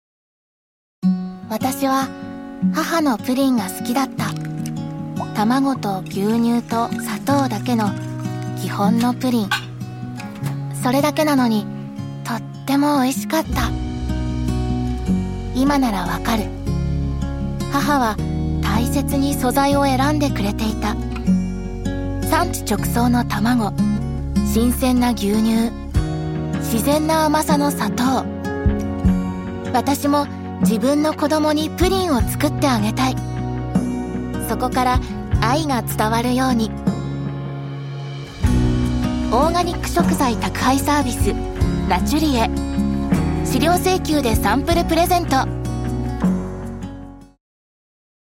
Amigáveis
Fresco
Suave